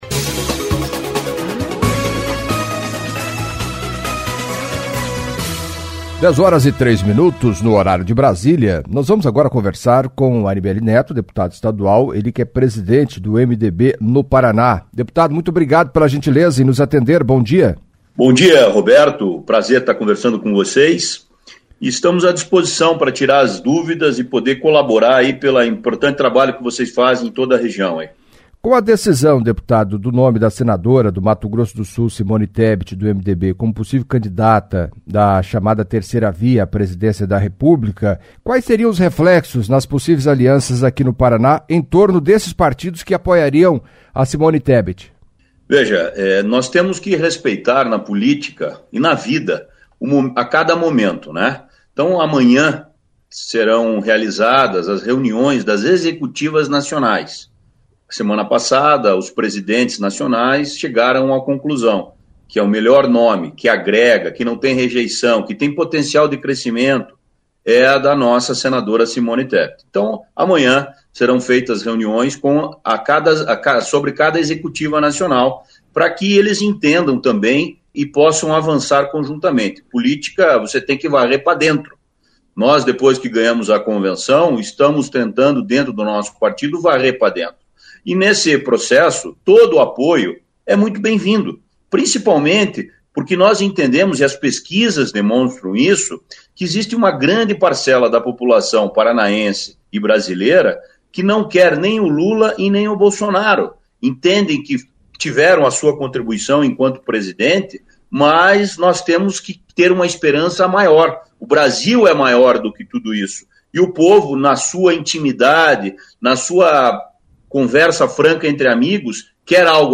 Em entrevista à CBN Cascavel nesta segunda-feira (23) o deputado estadual Anibelli Neto, presidente do MDB no Paraná, falou, entre outros assuntos, dos reflexos nas possíveis alianças envolvendo o partido no estado com Simone Tebet, senadora do Mato Grosso do Sul, sendo alçada para ser candidata à Presidência da República como alternativa na chamada "terceira via".